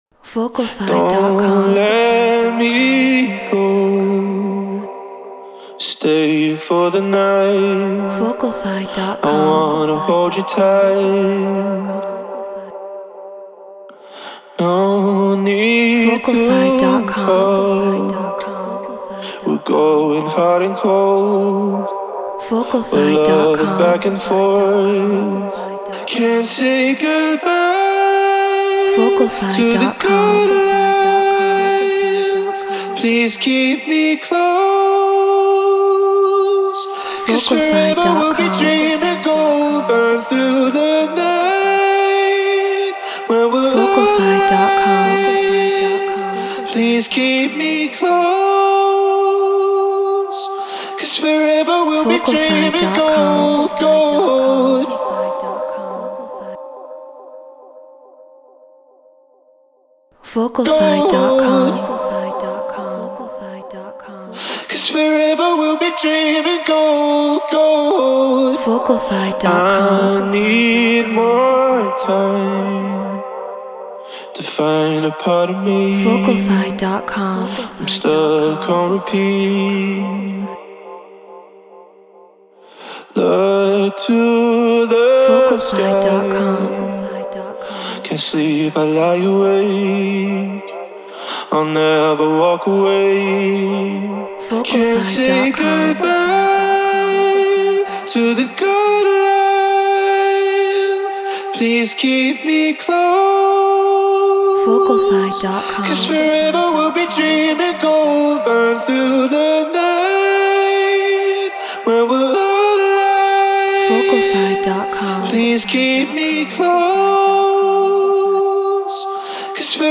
Eurodance 140 BPM Cmin
Shure SM7B Apollo Twin X Logic Pro Treated Room